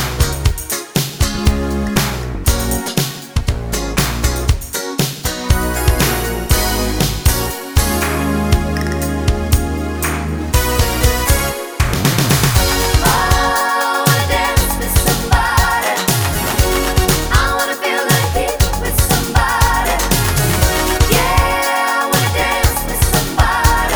Two Semitones Down Pop (1980s) 4:55 Buy £1.50